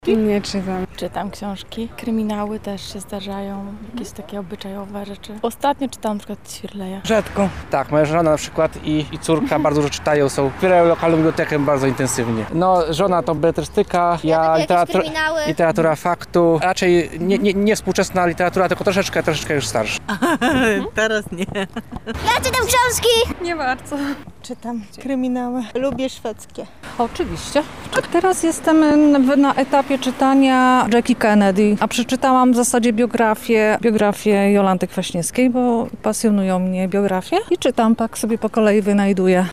Z tej okazji zapytaliśmy Lublinian, po jakie lektury sięgają najczęściej:
sondaksiazki.mp3